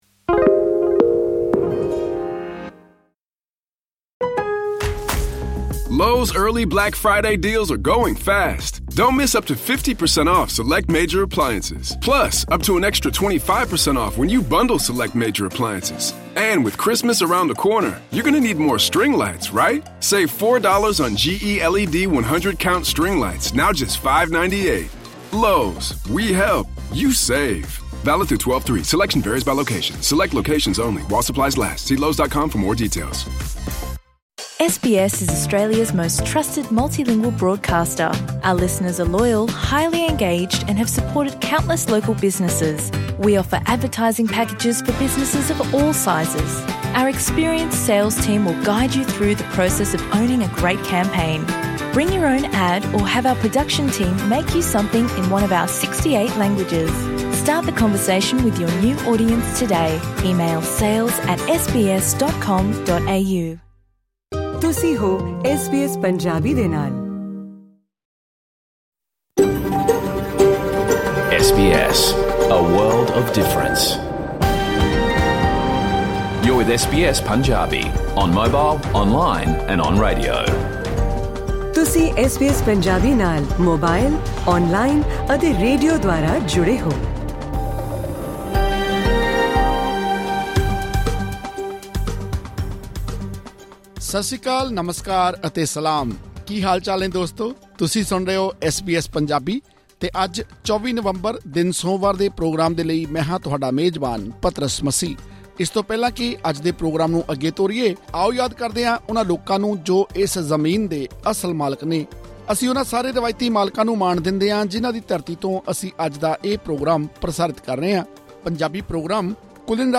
ਐਸ ਬੀ ਐਸ ਪੰਜਾਬੀ ਦੇ ਇਸ ਰੇਡੀਓ ਪ੍ਰੋਗਰਾਮ ਵਿੱਚ ਦੇਸ਼-ਵਿਦੇਸ਼ ਦੀਆਂ ਖ਼ਬਰਾਂ ਅਤੇ ਜਾਣਕਾਰੀ ਭਰਪੂਰ ਹੋਰ ਪੇਸ਼ਕਾਰੀਆਂ ਹਨ। ਜਿਨ੍ਹਾਂ ਵਿੱਚ ਆਸਟ੍ਰੇਲੀਆ ਸਰਕਾਰ ਵਲੋਂ ਵਿਦਿਆਰਥੀ ਵੀਜ਼ਿਆਂ ਦੇ ਨਿਯਮਾਂ ਵਿੱਚ ਕੀਤੀਆਂ ਗਈਆਂ ਤਾਜ਼ਾ ਤਬਦੀਲੀਆਂ, ਭਾਰਤੀ ਮਹਿਲਾ ਬਲਾਈਂਡ ਕ੍ਰਿਕਟ ਟੀਮ ਵਲੋਂ ਟੀ-ਟਵੰਟੀ ਵਰਲਡ ਕੱਪ ਜਿੱਤਣ ਬਾਰੇ ਅਤੇ ਪੰਜਾਬ ਵਿੱਚ ਸ੍ਰੀ ਗੁਰੂ ਤੇਗ ਬਹਾਦਰ ਸਾਹਿਬ ਦੇ 350ਵੇਂ ਸ਼ਹੀਦੀ ਪ੍ਰਕਾਸ਼ ਪੁਰਬ ਨੂੰ ਸਮਰਪਿਤ ਵਿਸ਼ੇਸ਼ ਸਮਾਗਮਾਂ ਦੇ ਵੇਰਵੇ ਸ਼ਾਮਿਲ ਹਨ। ਇਸ ਦੇ ਨਾਲ ਹੀ ਆਸਟ੍ਰੇਲੀਆ ਦੇ ਮੂਲ ਨਿਵਾਸੀਆਂ ਲਈ ‘ਨੇਟਿਵ ਟਾਈਟਲ’ ਦੀ ਅਹਿਮੀਅਤ ਬਾਰੇ ਚਾਨਣਾ ਪਾਉਂਦੀ ਇੱਕ ਖਾਸ ਰਿਪੋਰਟ ਵੀ ਮੌਜੂਦ ਹੈ।